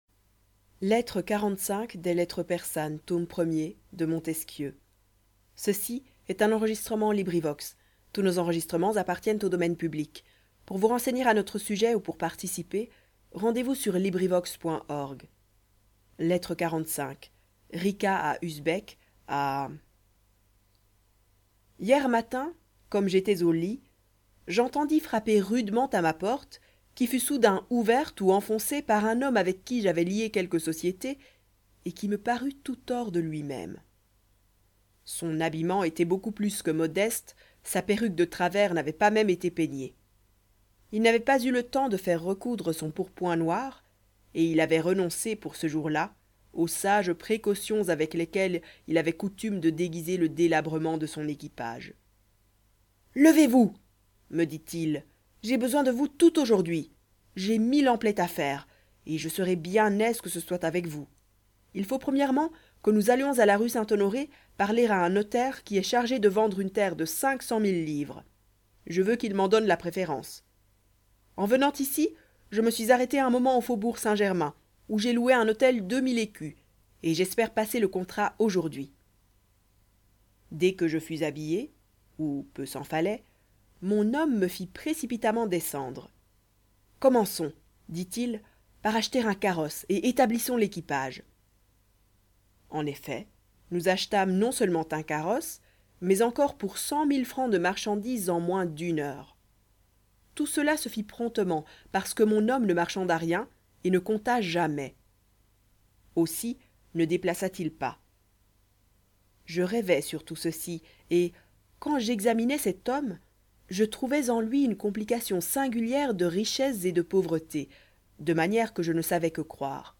LibriVox recording by volunteers. Lettre 45. Rica à Usbek, à *** .
Enregistrement LibriVox par des bénévoles.